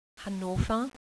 Read each word to yourself in the way that a German would pronounce it and then click on the sound icon next to each word to listen to it being spoken a German native speaker.
hannover.wav